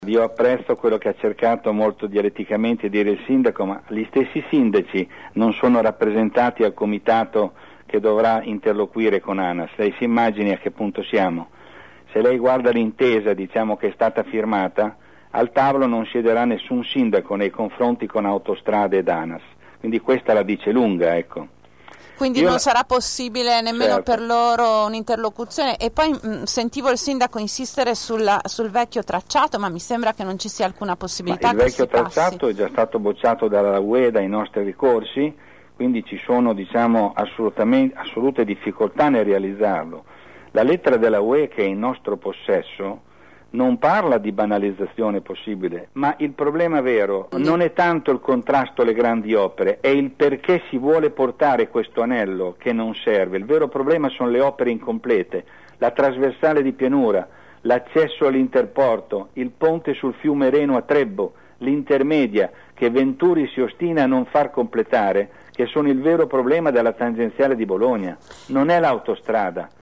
Ecco l’intervista